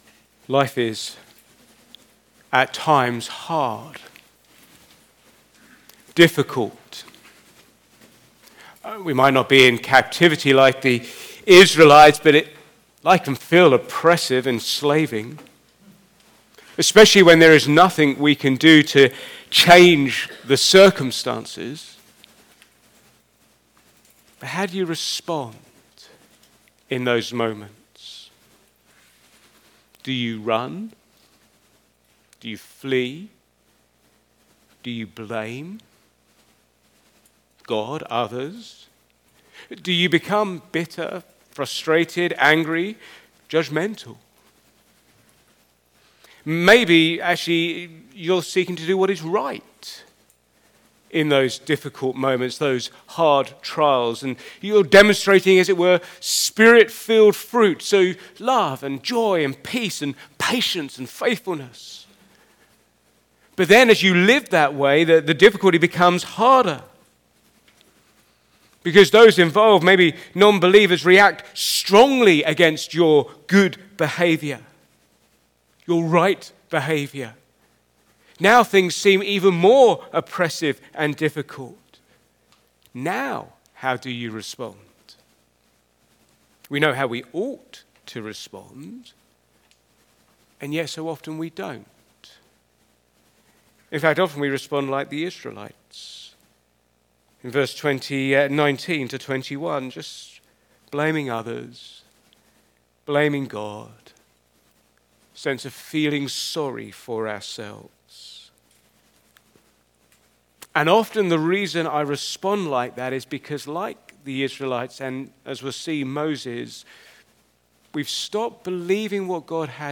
Back to Sermons The God who sets us free